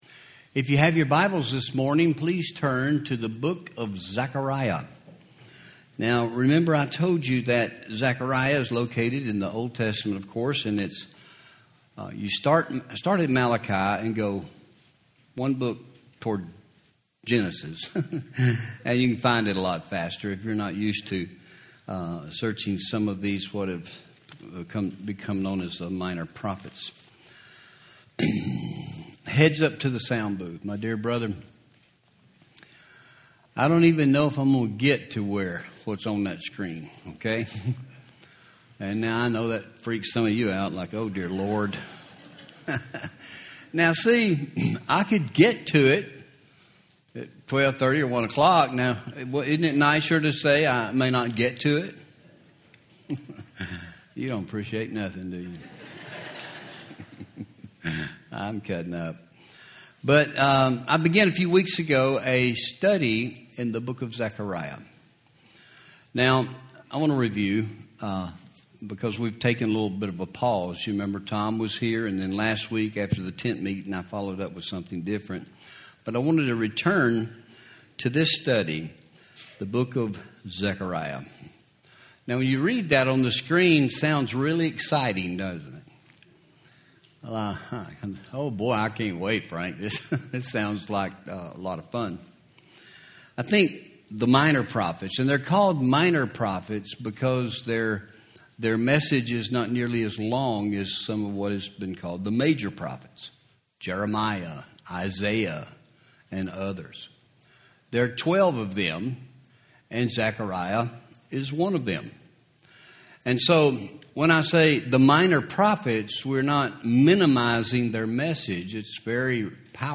Home › Sermons › A Study In The Book Of Zechariah